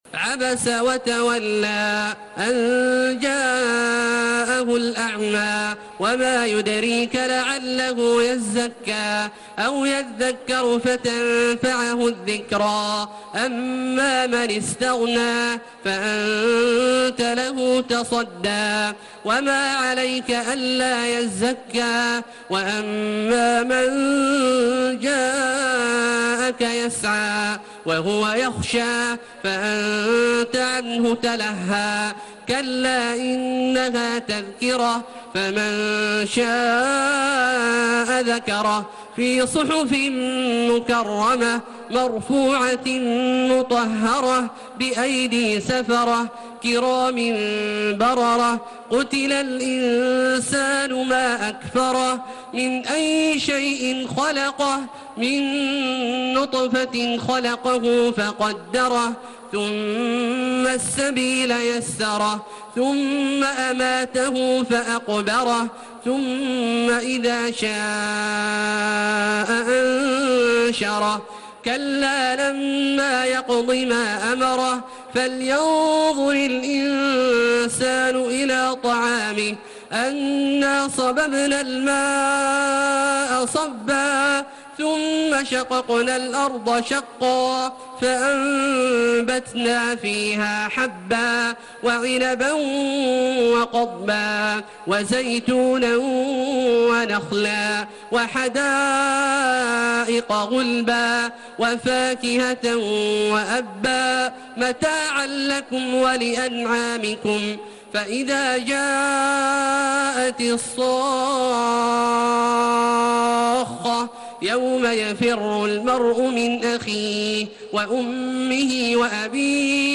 تراويح الحرم المكي 1432
مرتل